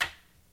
TC Clap Perc 02.wav